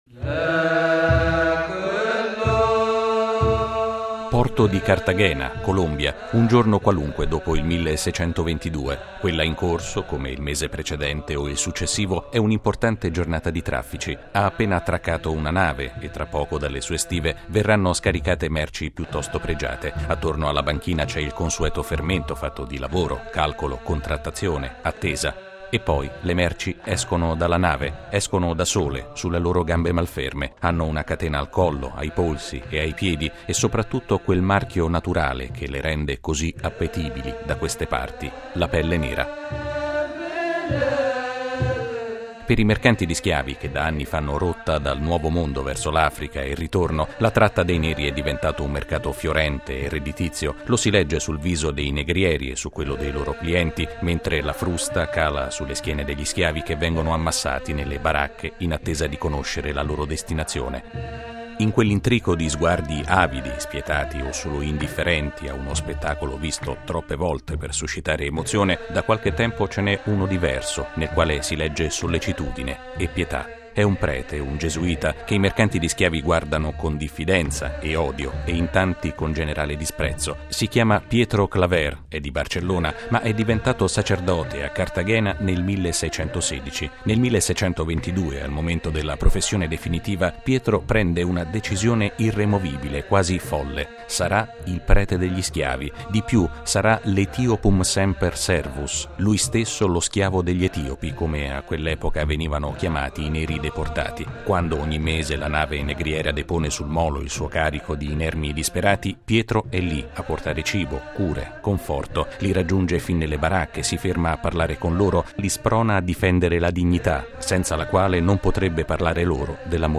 (canto)